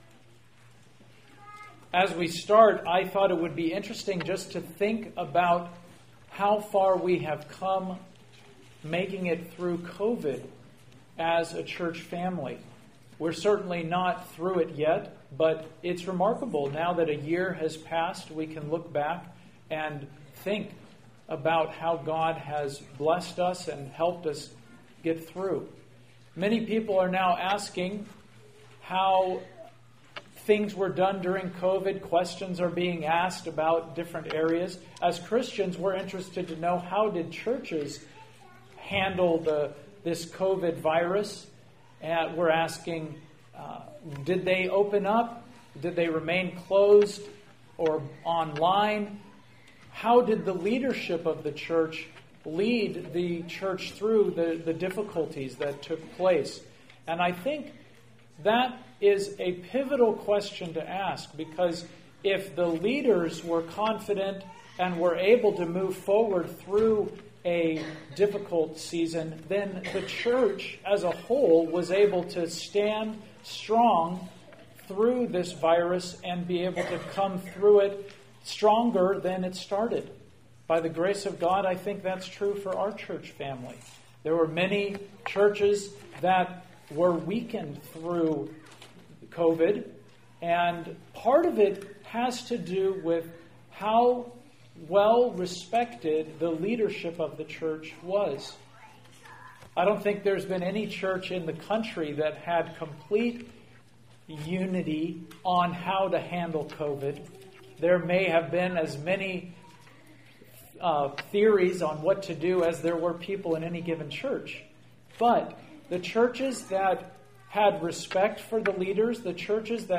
All Sermons